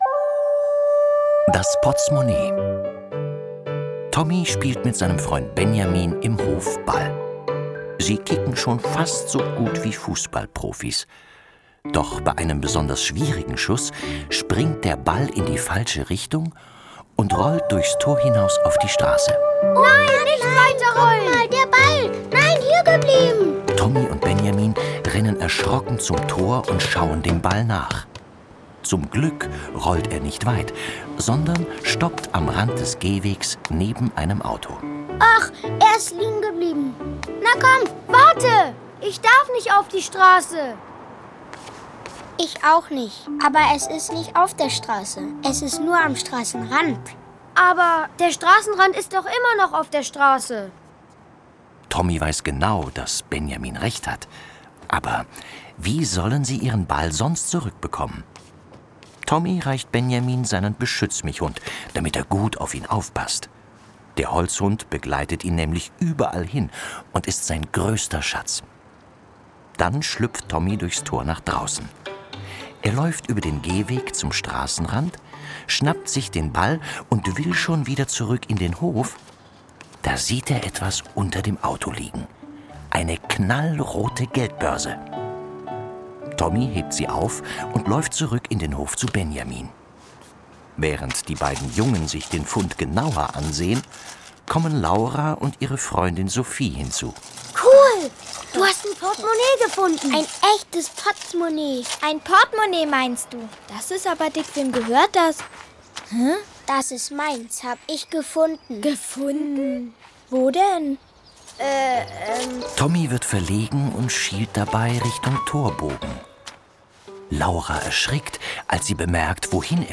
Tonspur der TV-Serie, Folge 7.